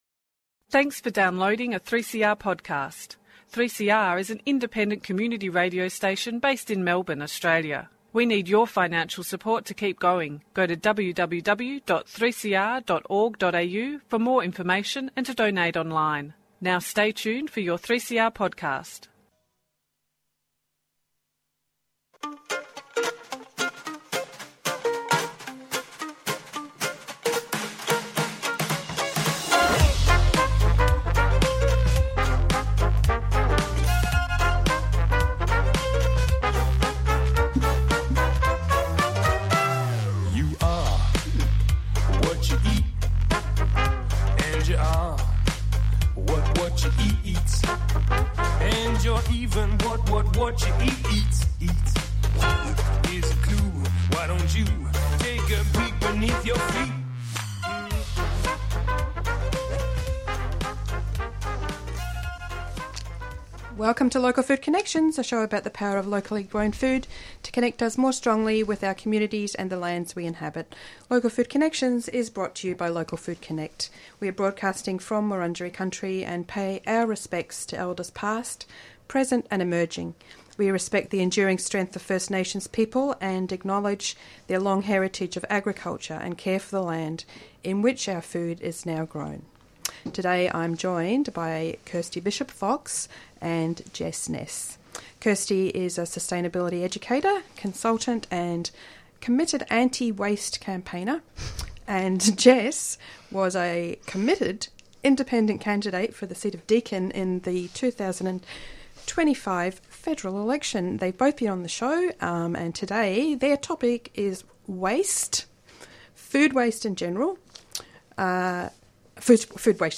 This is the second part of a two part interview.